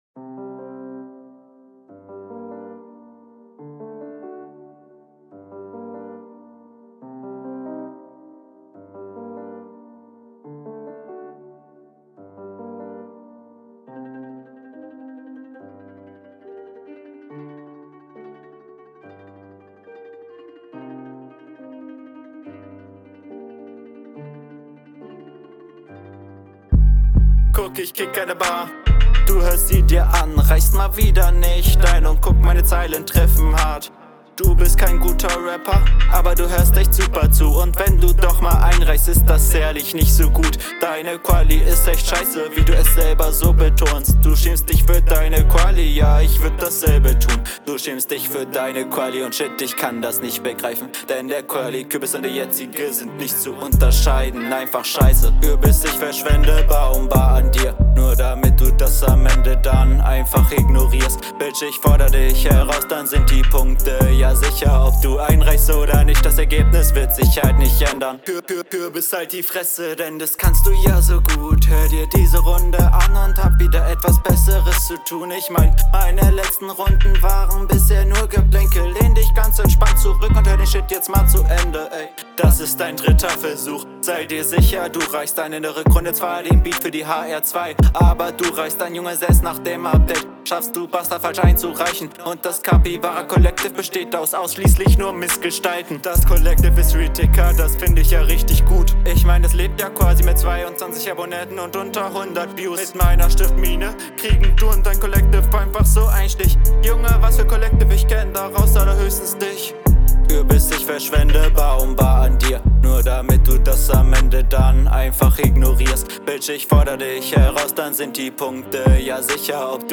Zuuu viele flowfehler und das dauerhafte Autotune nervt auch nach ner zeit